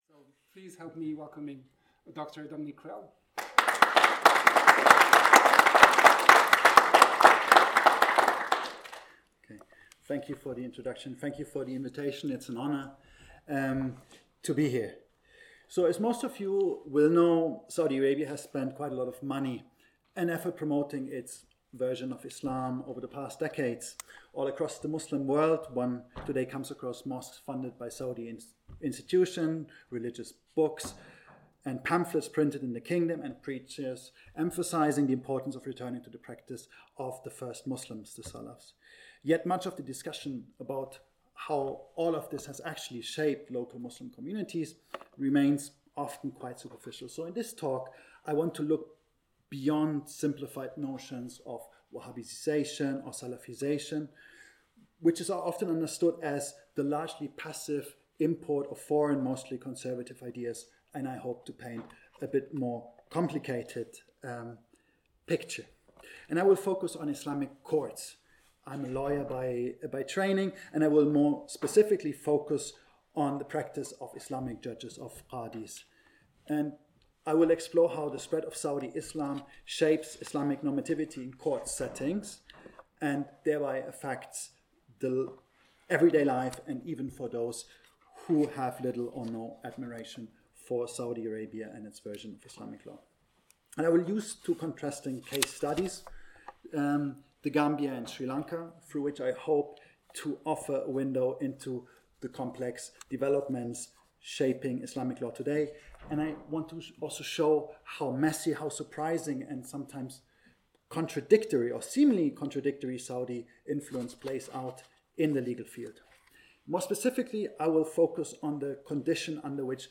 This talk explores how Saudi legal thought is shaping the ways in which Islamic law is applied by Islamic courts beyond the Arabian Peninsula. Since the 1960s, Saudi Arabia has made significant efforts to promote a distinct Saudi understanding of Islam globally, mainly through international students at Saudi universities such as the Islamic University of Medina.